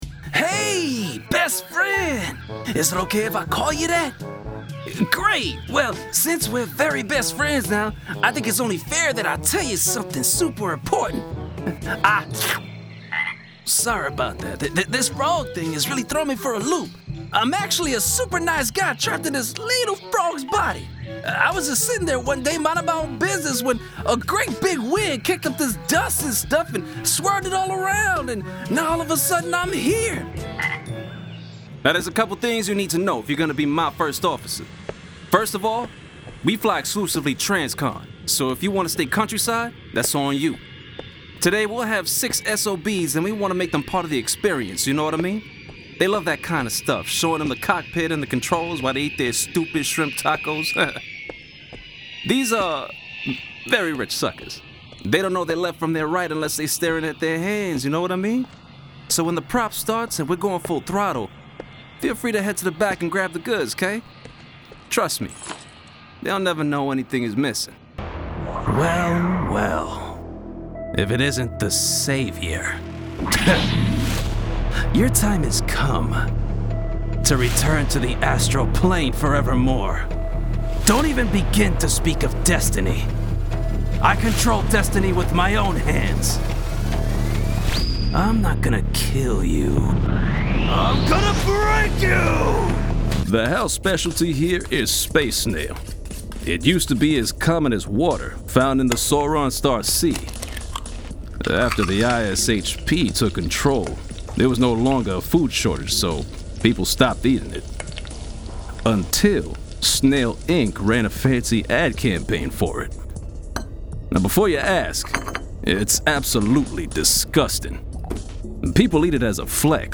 Animation Reel
I use a Deity S-mic 2 Shotgun Mic with a Scarlett SOLO FocusRite preamp, and a padded padded area for sound regulation.